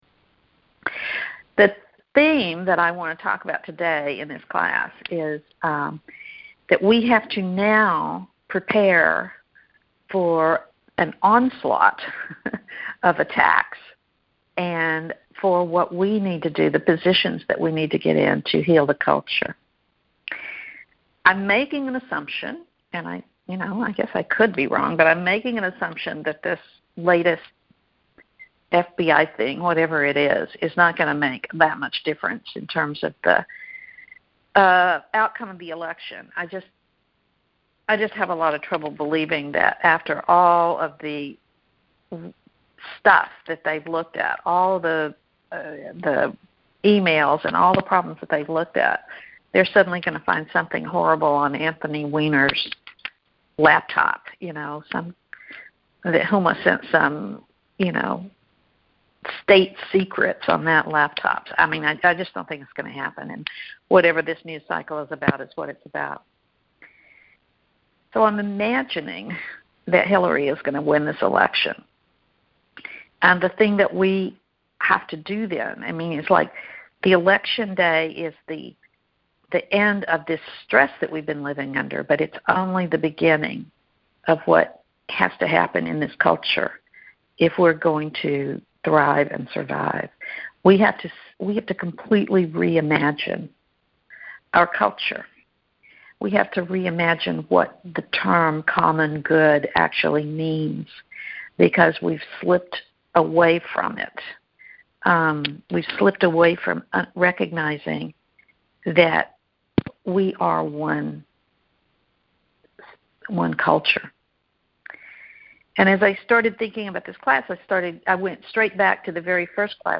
As the campaign comes to an end, it is now time for us to start the delicate and important work of healing and reconstructing our society. I hope that listening to this recording of one of our classes will soothe your nerves, clarify your intentions, and encourage you to join hands with us as we “go high!”